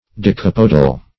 Search Result for " decapodal" : The Collaborative International Dictionary of English v.0.48: Decapodal \De*cap"o*dal\, Decapodous \De*cap"o*dous\, a. (Zool.)